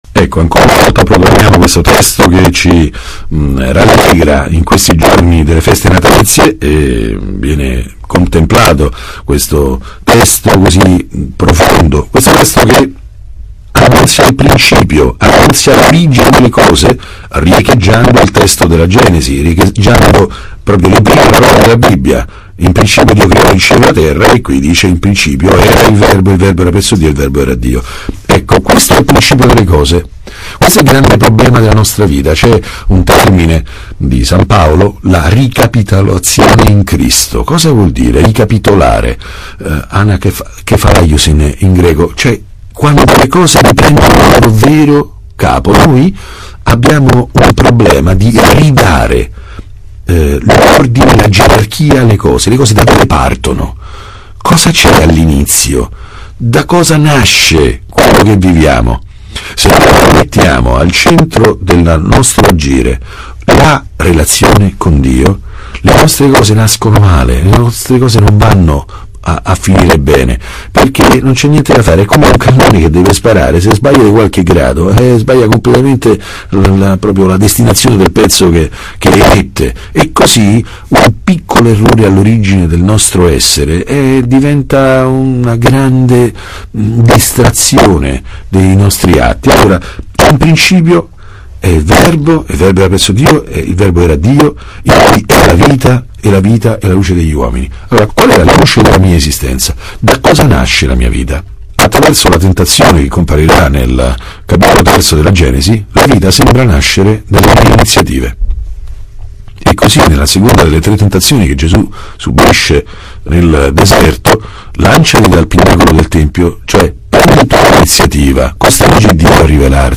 II Domenica dopo Natale - Commento al Vangelo